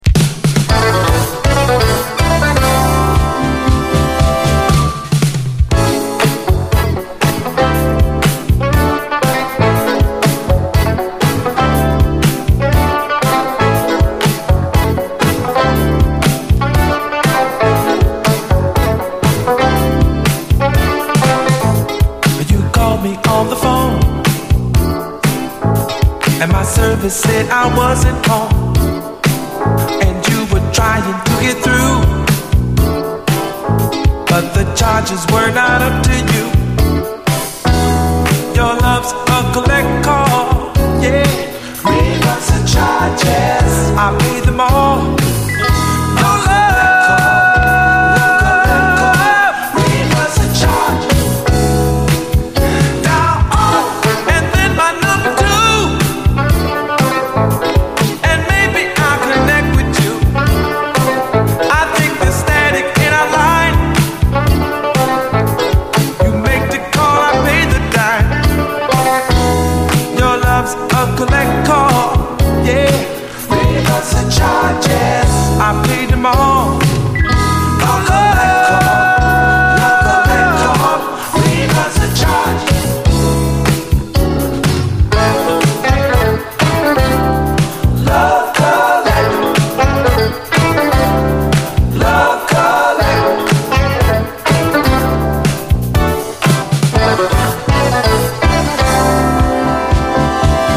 ビート感といい、とにかくファット！
ジャズ・ファンク